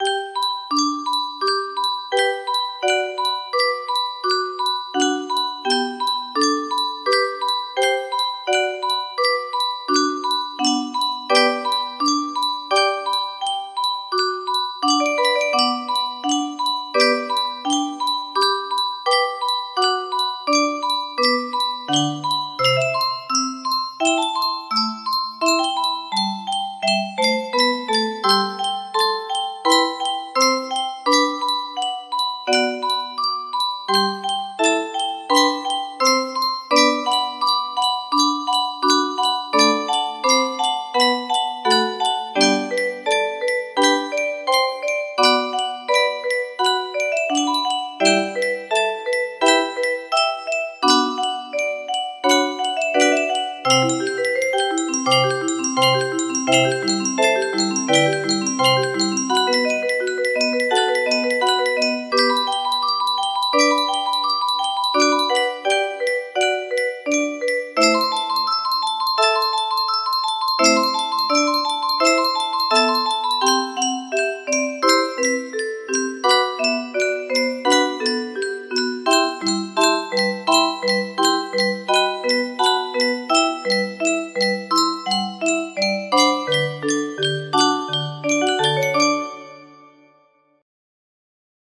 scissor music box melody
Yay! It looks like this melody can be played offline on a 30 note paper strip music box!